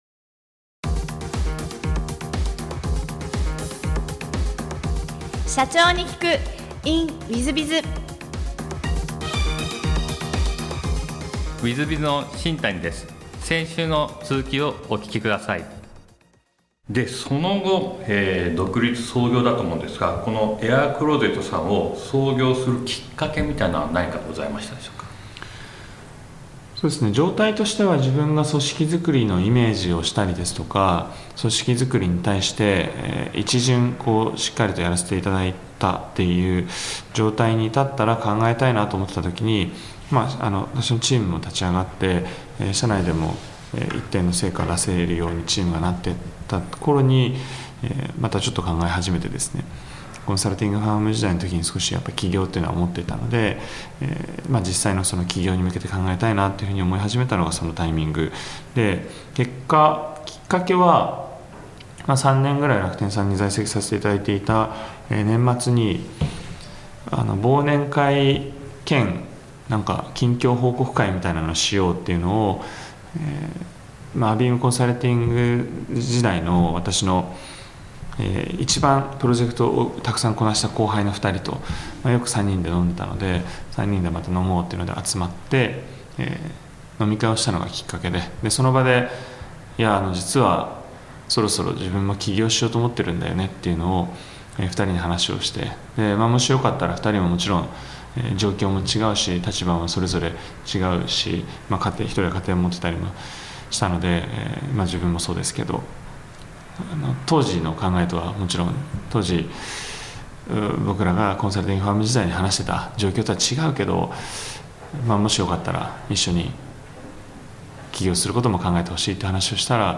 普段着に特化したレンタルサービス業で業績を伸ばし、上場を果たしたエピソードから経営のヒントが得られます。ぜひ、インタビューをお聞きください。